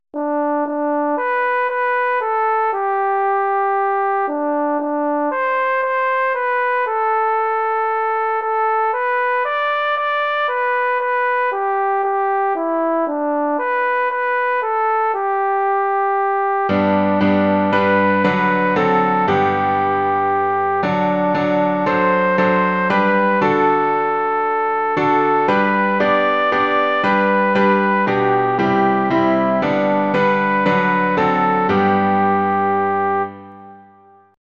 Muzica: Melodie rusă